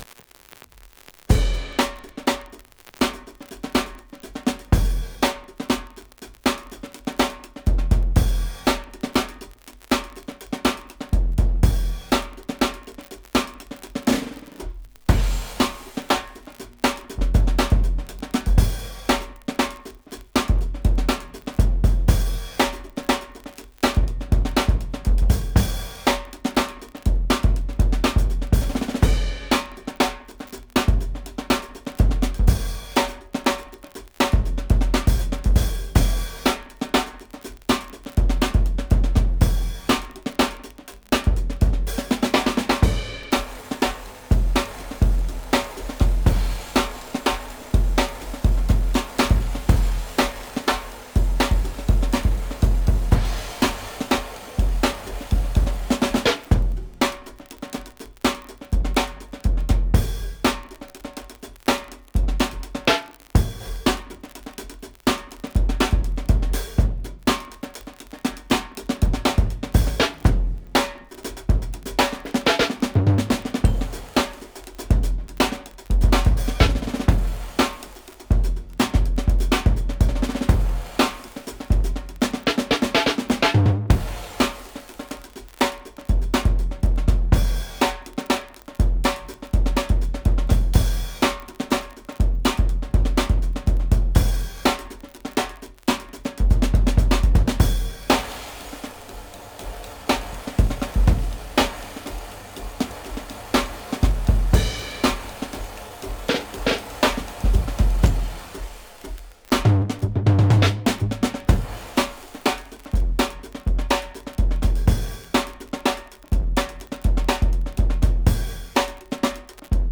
CHILL DRUM & BASS BEATS.wav